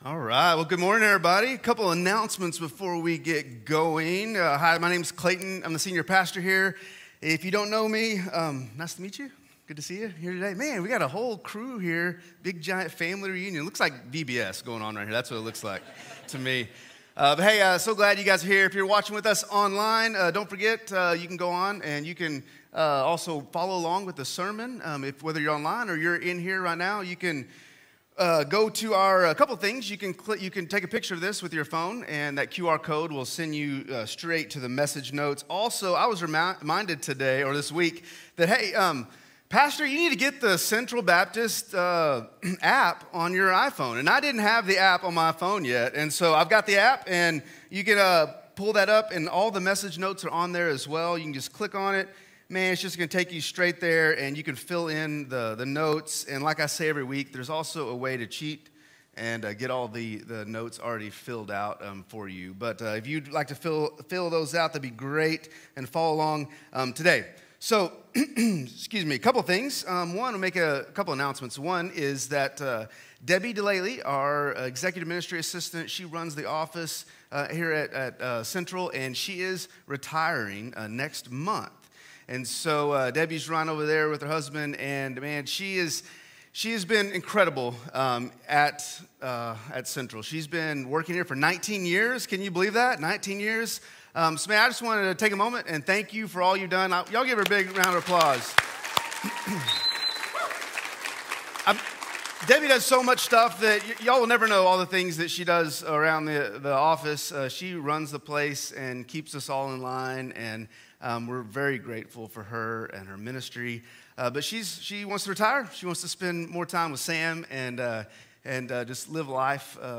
Join us for todays 10:45am service at CBC.